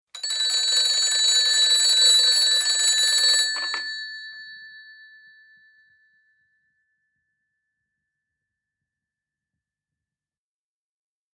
Old british desk telephone, hand-cranked type ringing and pick up